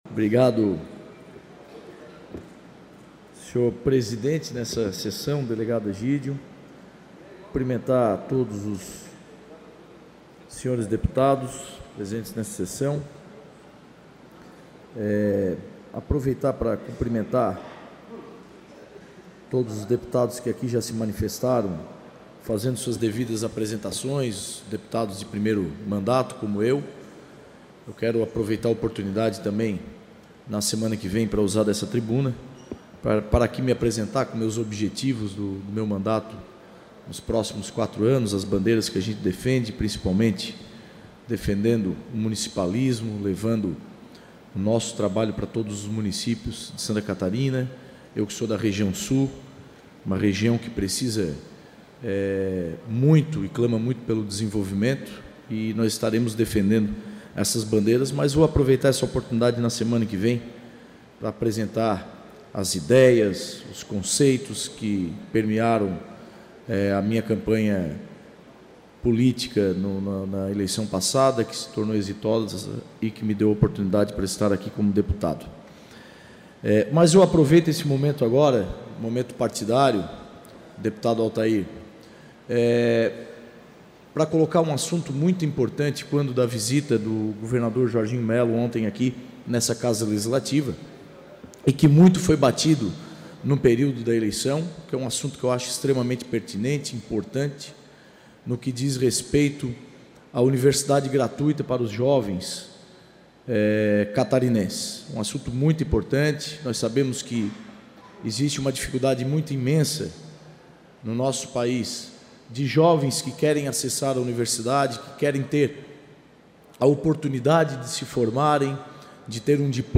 Pronunciamentos da sessão ordinária desta quarta-feira (8)
Confira os pronunciamentos dos deputados na sessão ordinária desta quarta-feira (8):